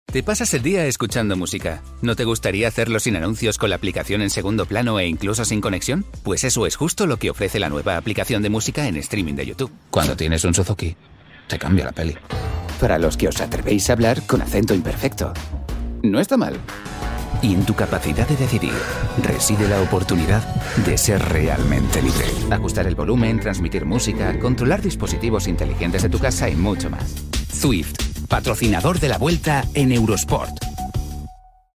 Male
Spanish (Native)
Young, warm and versatile friendly voice. Fresh, casual or serious and bold.
Animation.mp3
Microphone: Sennheiser MKH416, Neumann TLM103
Audio equipment: Focusrite ISA, TL Audio VP1, Audient iD22, Soundproofed studio